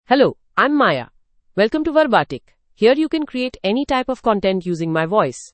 Maya — Female English (India) AI Voice | TTS, Voice Cloning & Video | Verbatik AI
FemaleEnglish (India)
Maya is a female AI voice for English (India).
Voice sample
Listen to Maya's female English voice.
Maya delivers clear pronunciation with authentic India English intonation, making your content sound professionally produced.